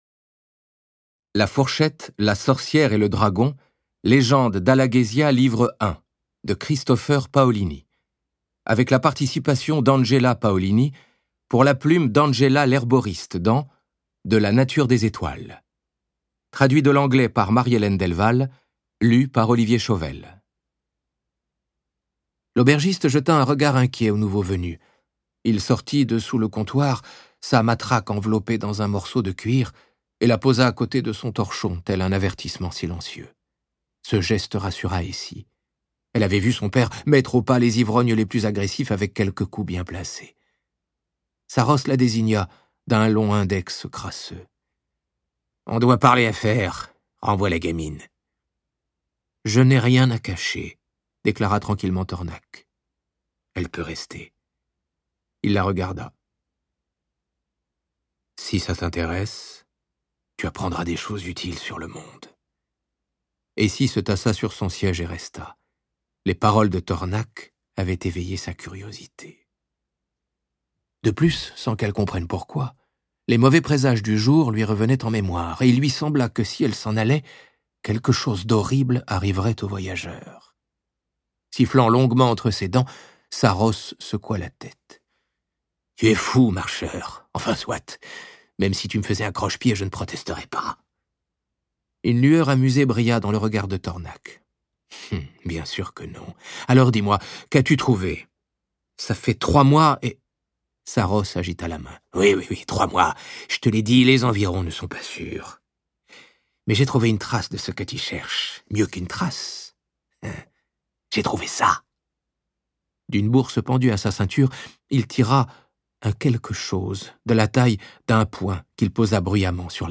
Diffusion distribution ebook et livre audio - Catalogue livres numériques